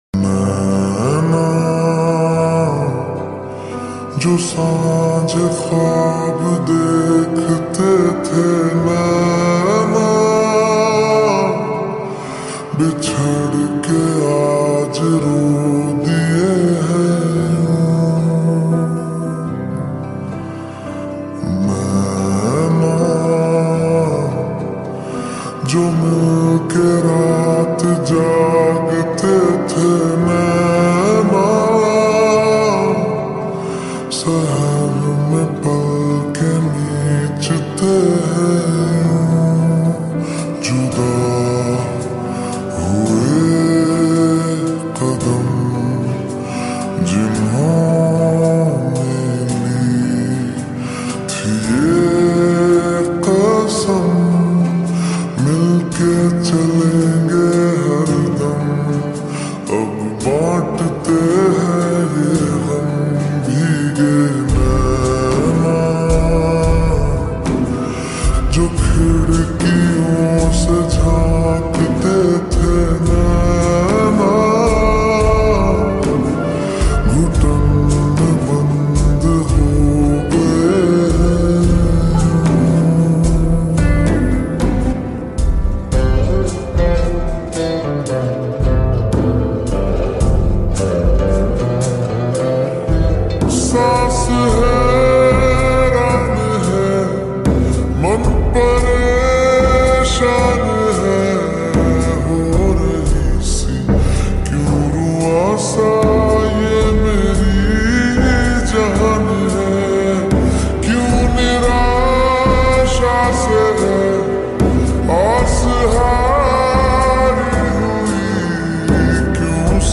Full Song Slowed And Reverb